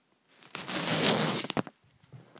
This is a short pull … This tear doesn’t hit the same low end that the longer ones do, but it has a good flow to it with a nice snap at the end.
Wallpaper3.aiff